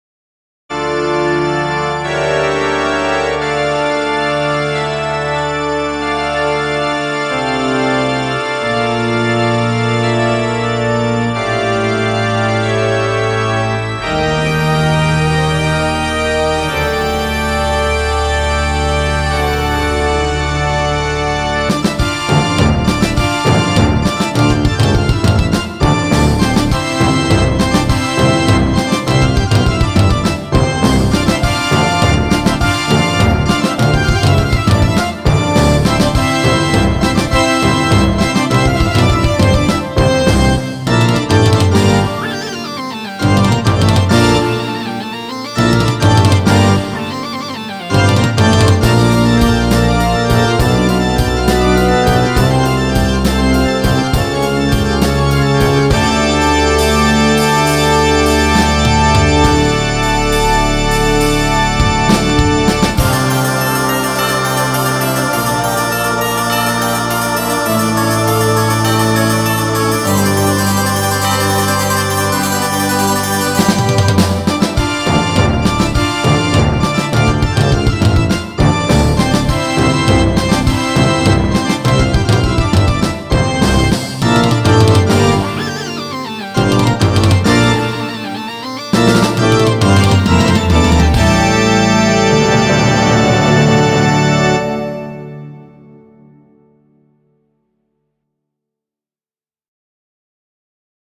BPM90-203
Audio QualityPerfect (High Quality)
Comments[CATHEDRAL]